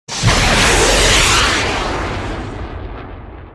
missile.ogg